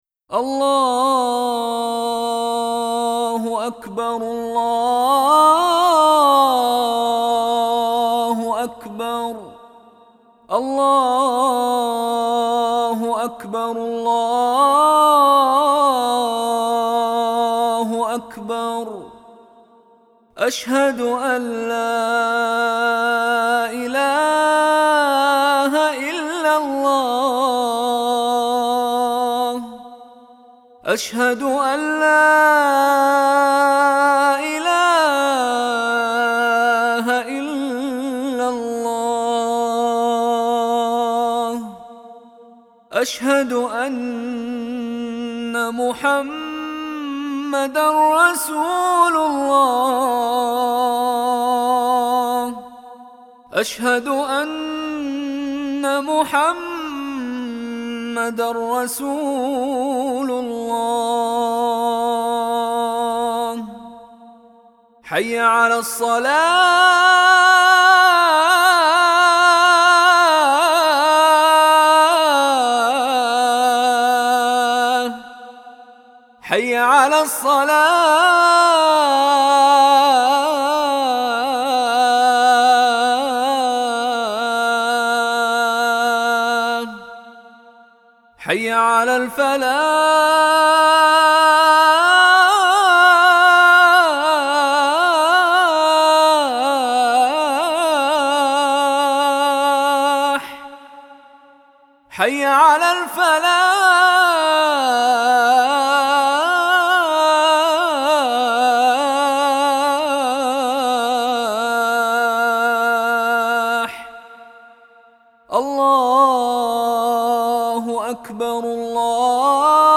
Athan.BJUhzuni.mp3